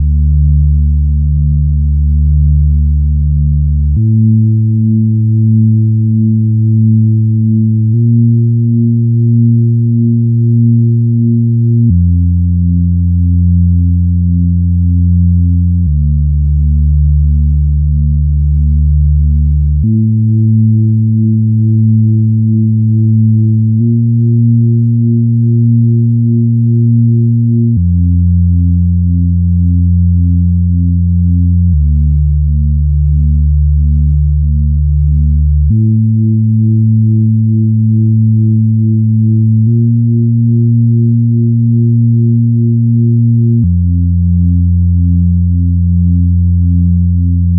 🔹 50 Premium Serum Presets crafted for melodic house, cinematic soundscapes, and deep emotional productions.
Preset Preview
Deep & Warm Basses – Rich low-end for melodic house grooves
RearView-Lights-0011-Instrument-BA-Deep-Ember.wav